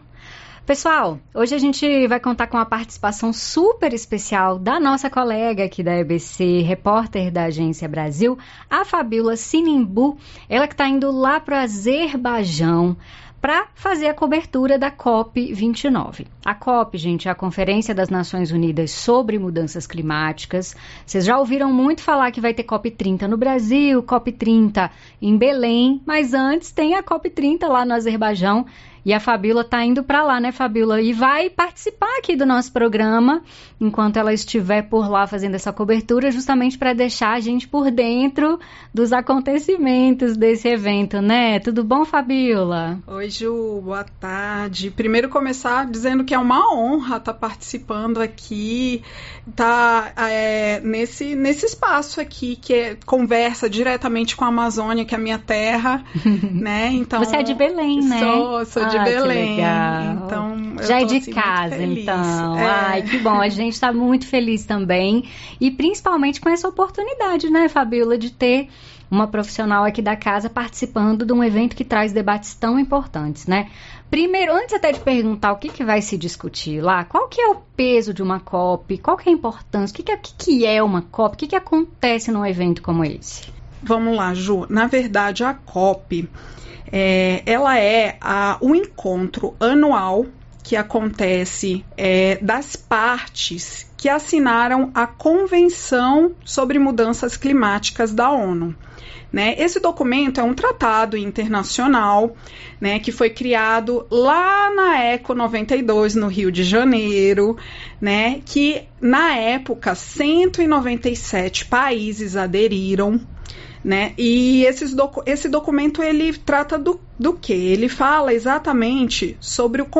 O programa Tarde Nacional conversou com